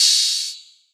DDW2 OPEN HAT 3.wav